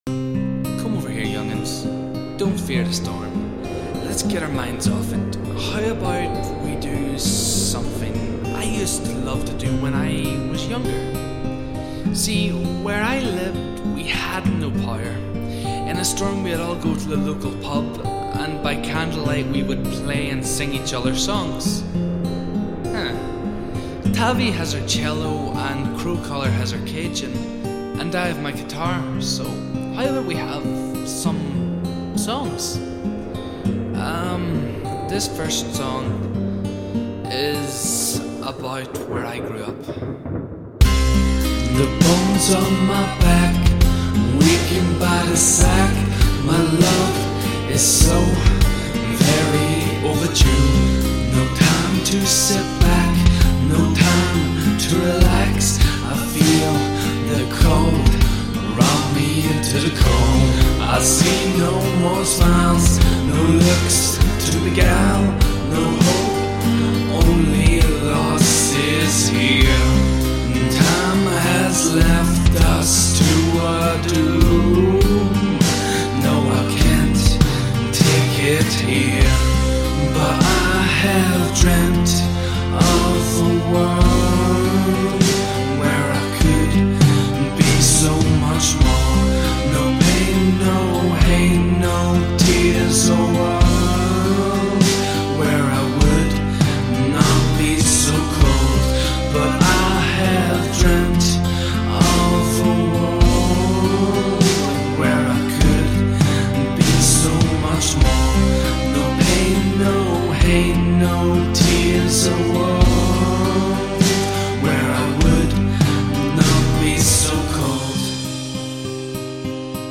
Genre - Acoustic / Celtic Folk